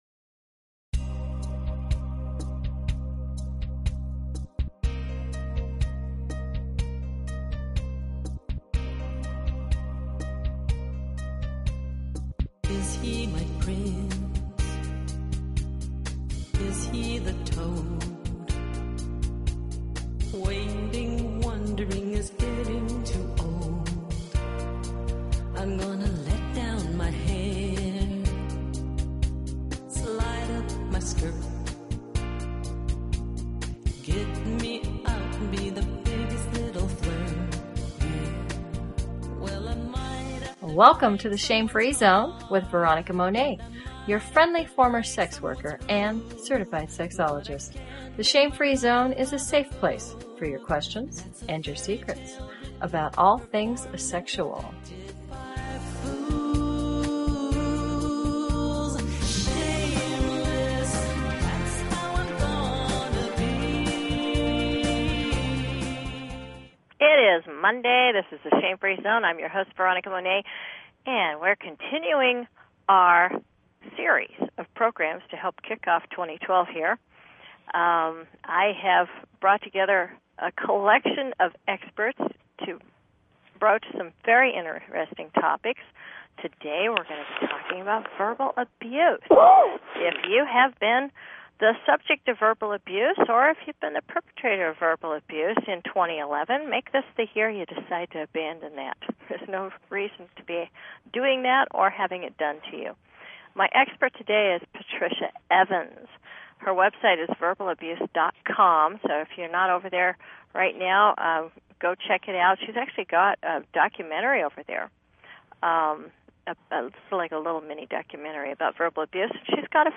Talk Show Episode, Audio Podcast, The_Shame_Free_Zone and Courtesy of BBS Radio on , show guests , about , categorized as